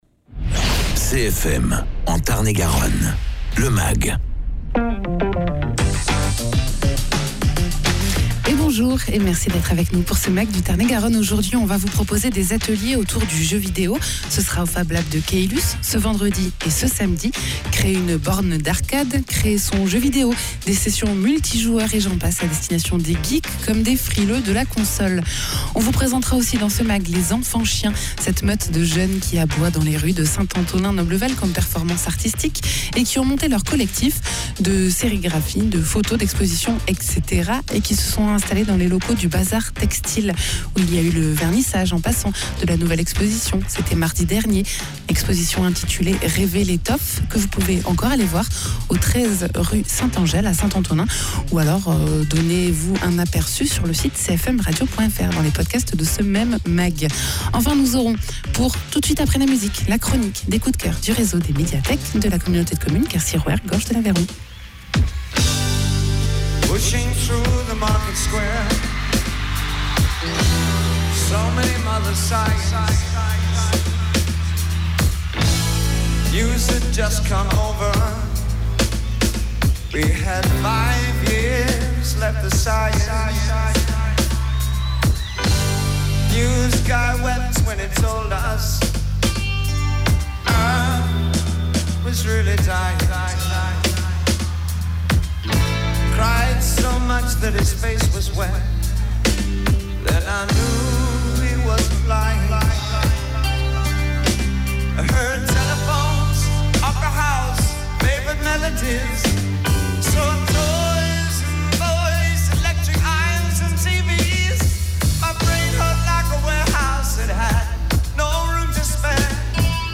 Pour les geeks ou les novices, une série d’ateliers autour du jeu vidéo aura lieu ce vendredi et ce samedi au Fablab de Caylus. Egalement dans ce mag, reportage chez les Enfants chiens, un collectif autour de la sérigraphie, mais pas que, qui a son atelier au Bazart Textile de St Antonin Noble Val.